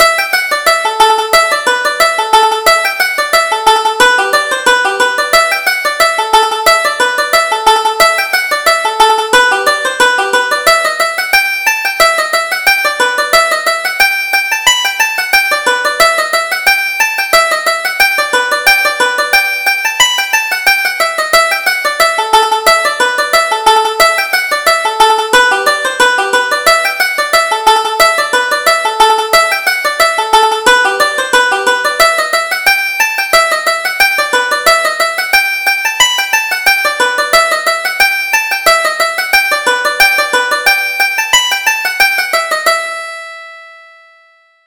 Reel: Peggy on the Settle